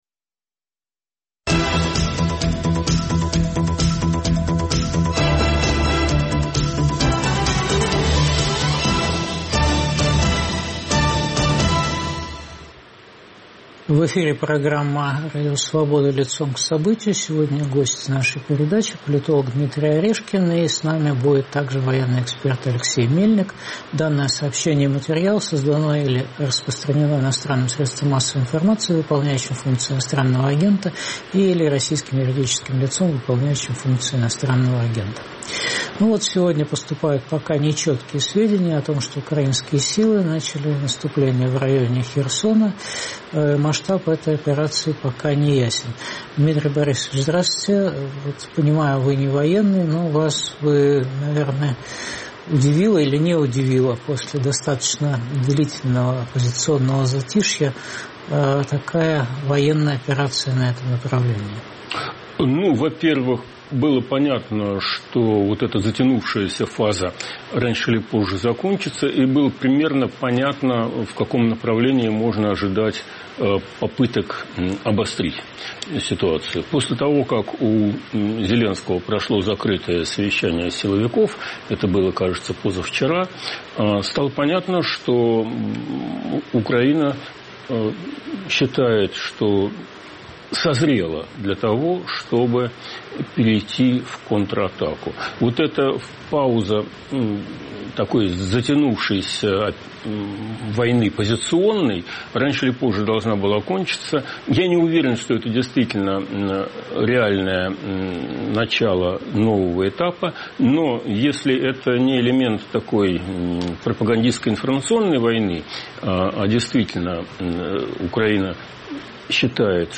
Какой будет судьба путинизма в России? В эфире политолог Дмитрий Орешкин.